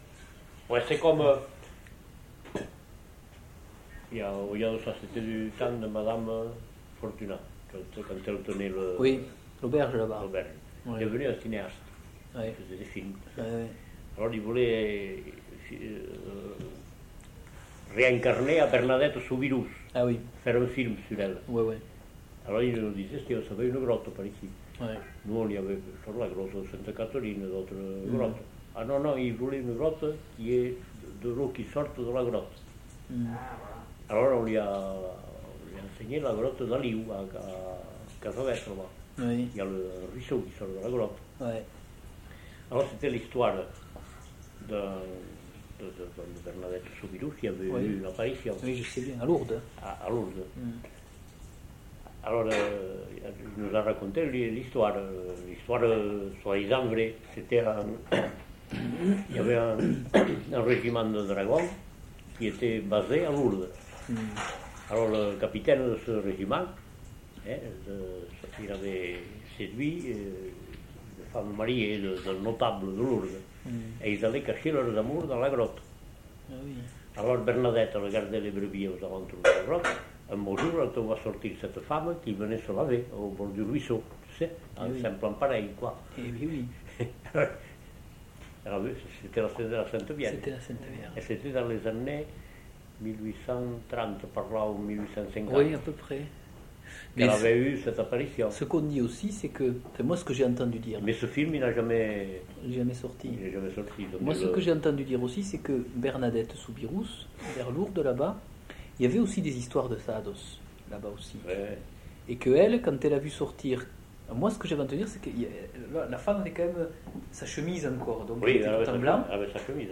Lieu : Pouech de Luzenac (lieu-dit)
Genre : conte-légende-récit
Type de voix : voix d'homme
Production du son : parlé
Classification : récit légendaire